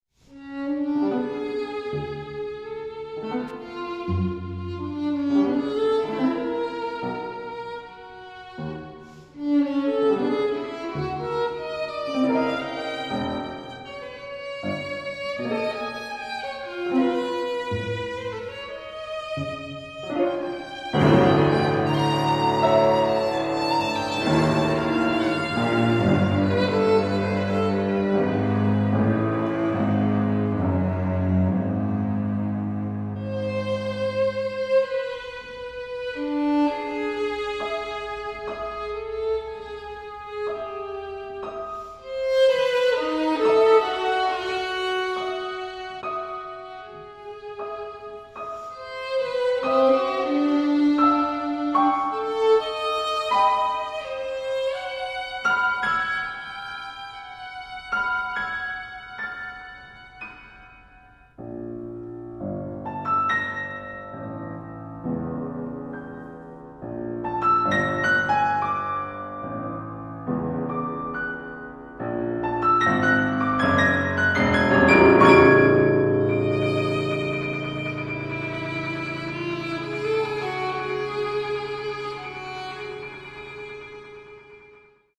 violin, Cello, Piano